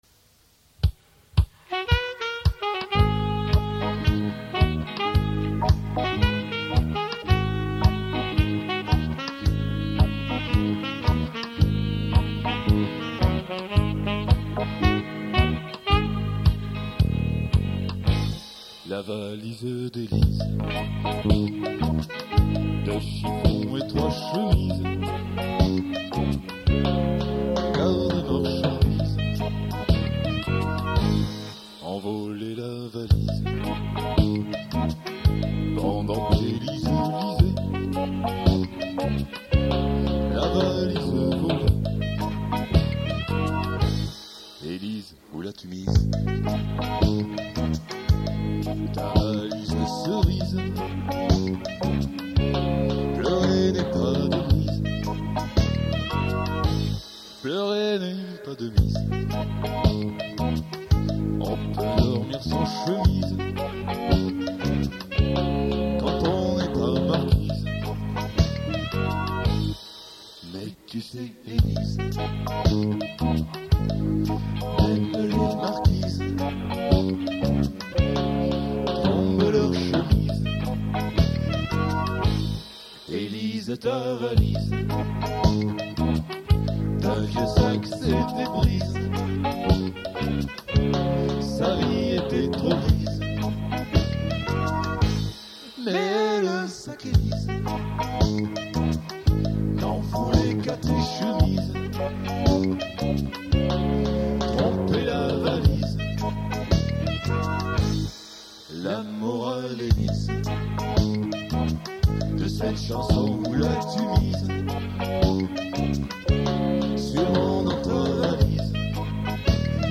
enregistré sur K7en 1989 !!